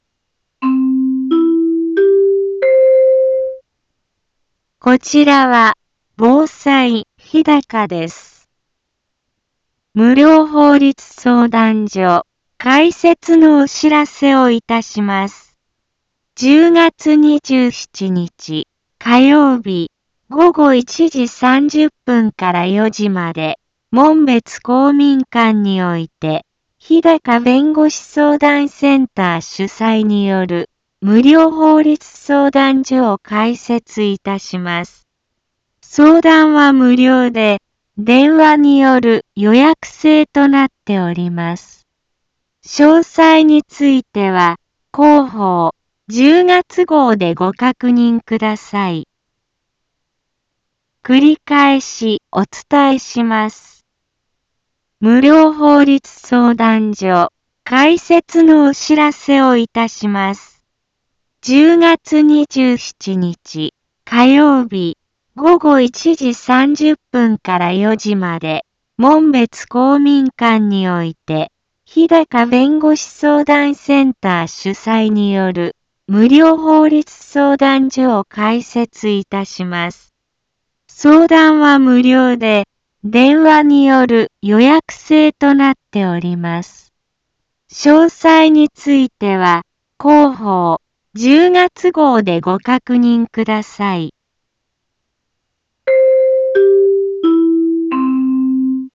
一般放送情報
BO-SAI navi Back Home 一般放送情報 音声放送 再生 一般放送情報 登録日時：2020-10-20 10:03:29 タイトル：無料法律相談所 インフォメーション：１０月２７日、火曜日、午後1時30分から4時まで、門別公民館において、ひだか弁護士相談センター主催による、無料法律相談所を開設いたします。